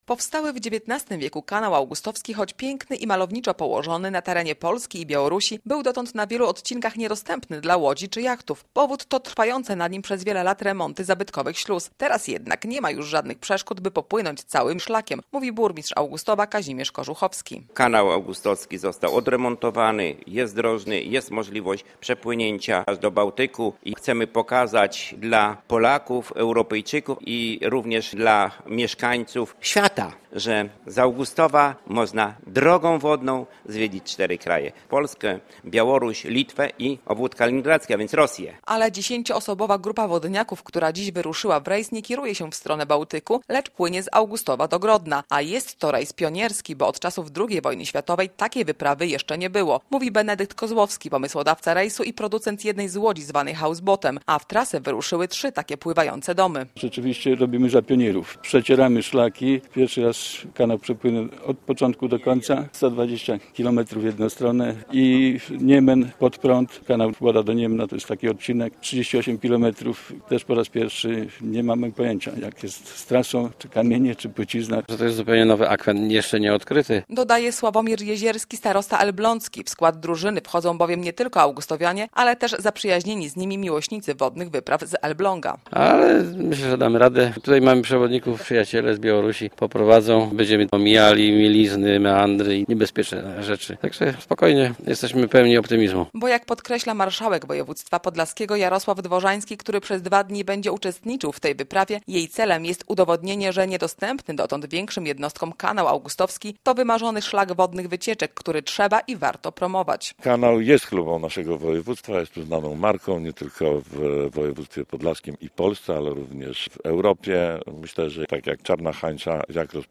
Historyczny rejs Kanałem Augustowskim - relacja